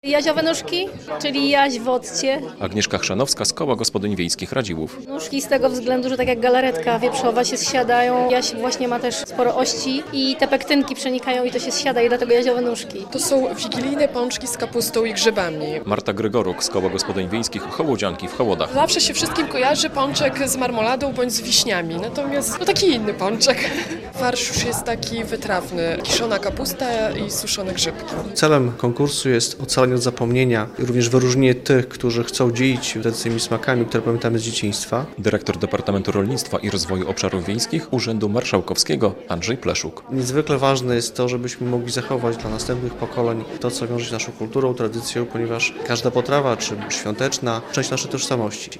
Produkty regionalne z Podlasia nagrodzone "Perłami" 2023 - relacja